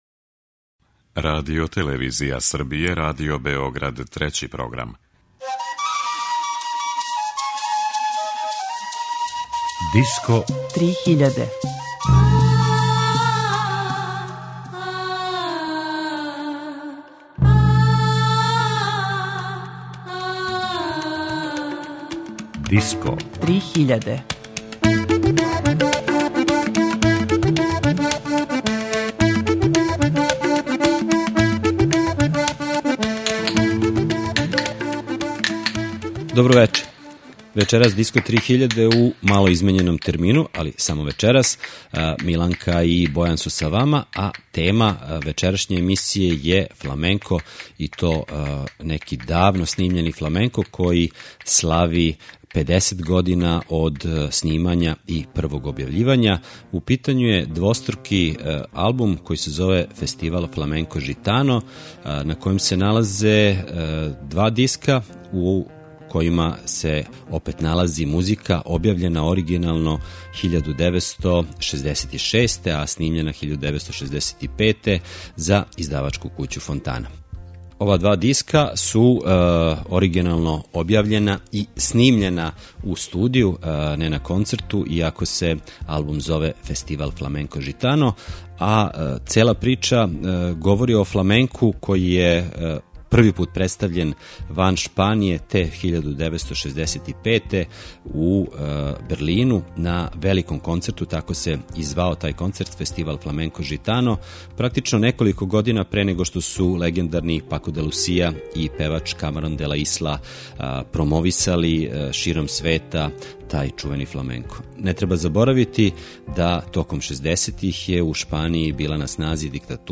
Емисија је посвећена фламенку, једном од најзаступљенијих world music жанрова 20. века.
Фламенко се налази на UNESCO-вој листи нематеријалног културног наслеђа, а снимци које ћете слушати су начињени пре 50 година, након првог концерта фламенко музике ван Шпаније.